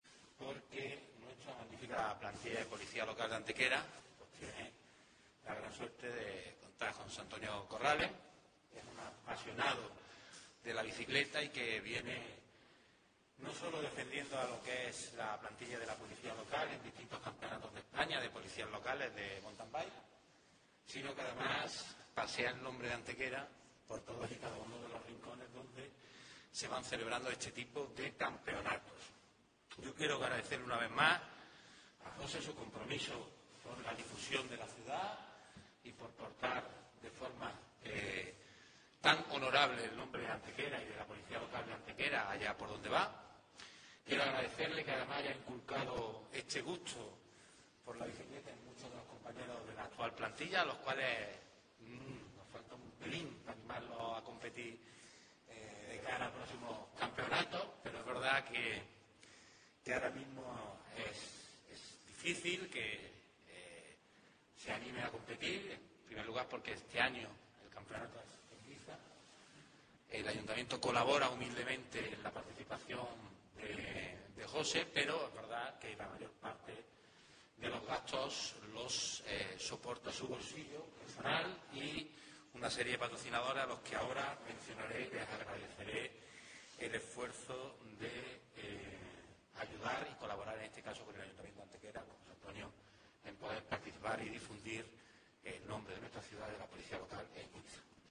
El teniente de alcalde delegado de Seguridad y Tráfico, Ángel González, ha anunciado hoy en rueda de prensa la participación de Antequera en el inminente Campeonato de España de Mountain Bike para Policías Locales, prueba deportiva que se desarrollará en Ibiza este sábado 27 de septiembre.
Cortes de voz A. González 1152.12 kb Formato: mp3